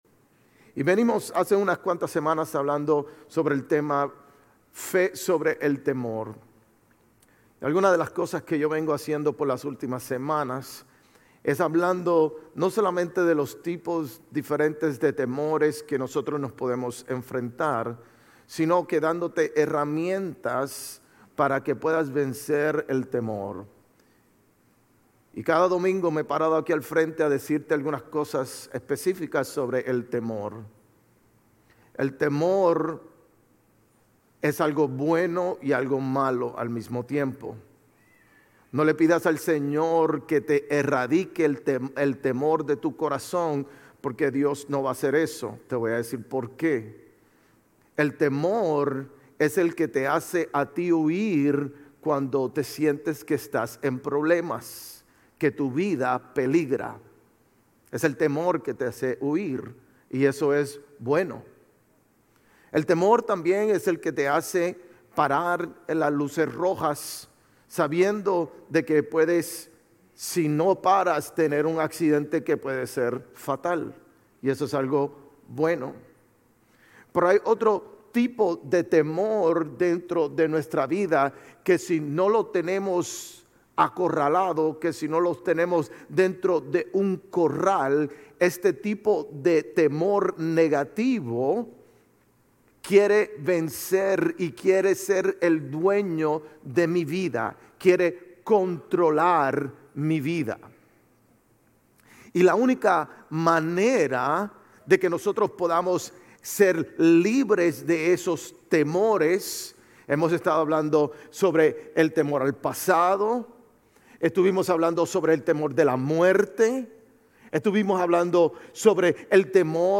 Sermones Grace Español 6_15 Grace Espanol Campus Jun 16 2025 | 00:43:07 1x 00:00 / 00:43:07 Subscribe Share RSS Feed Share Link Embed